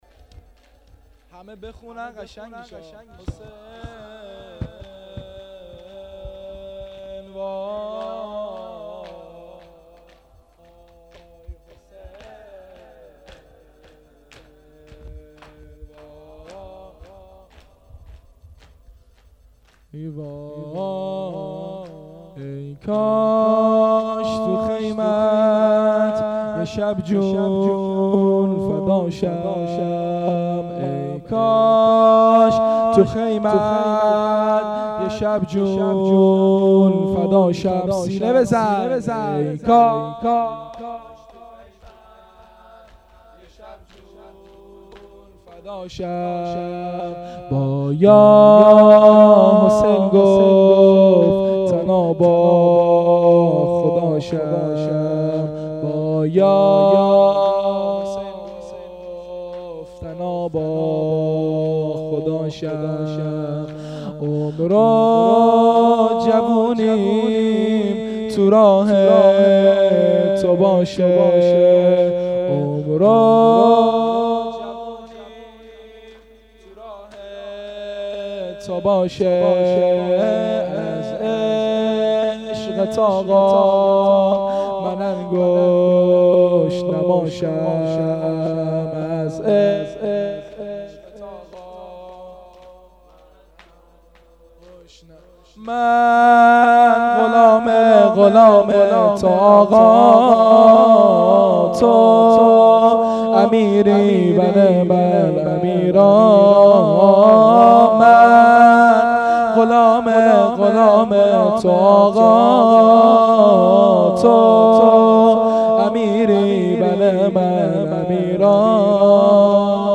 مداحی تک